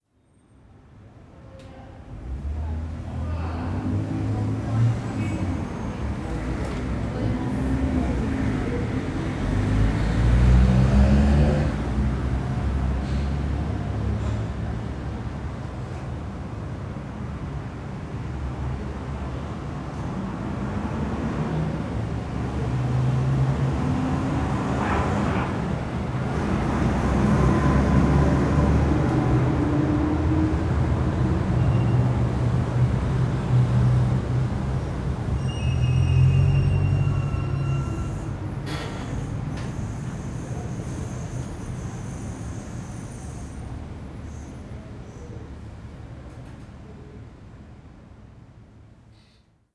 Ambiente interior de bar con pasadas de coches en el exterior